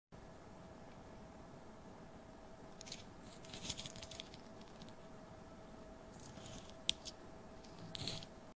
Cake Eating 🍰 ASMR.